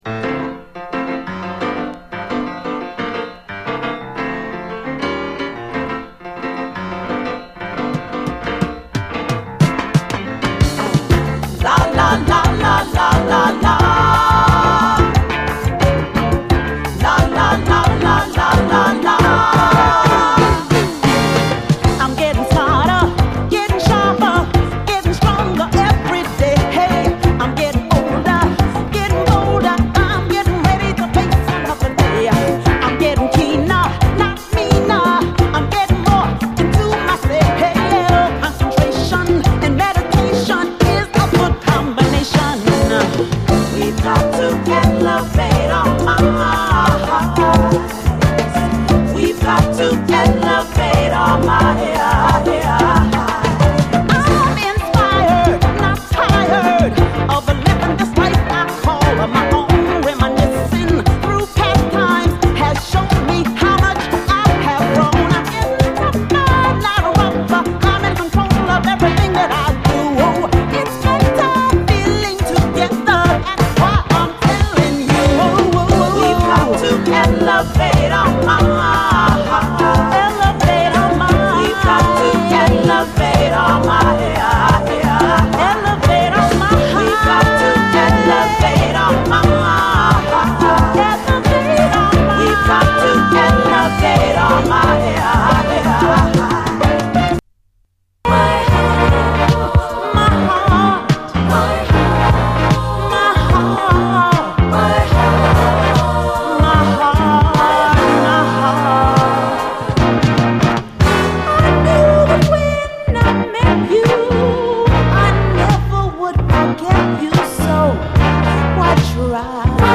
SOUL, 70's～ SOUL, DISCO
都会的なムード輝くレディー・ソウル名盤！